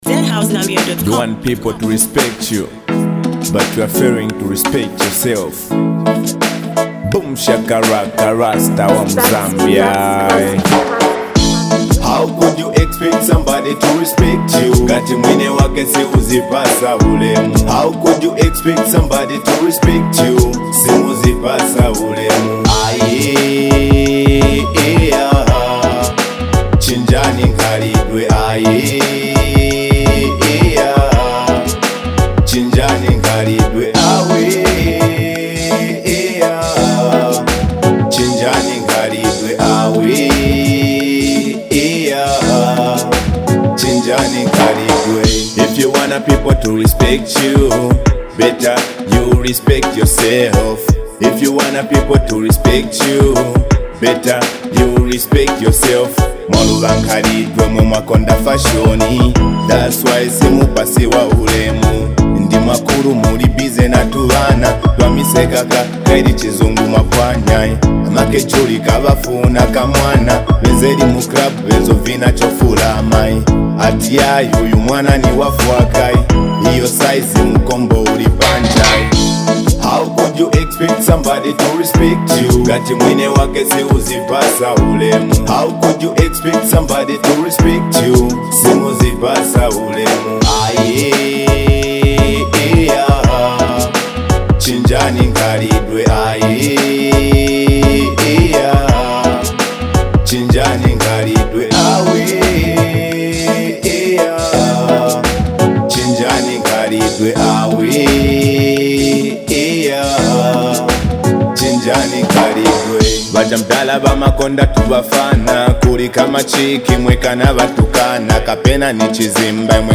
a powerful anthem